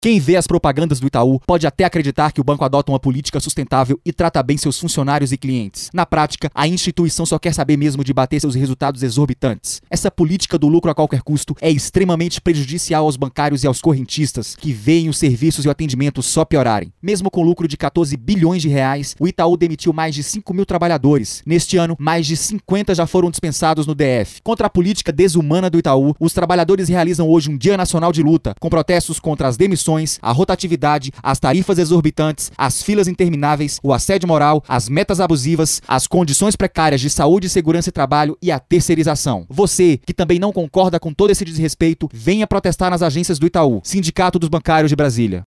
Além de apoiar as atividades nas agências da W3 Sul e do Setor Comercial Sul, o Sindicato colocou spots nas rádios locais e espalhou outdoors em alguns pontos do DF para informar a população sobre os problemas enfrentados pelos bancários. Clique aqui para ouvir o spot.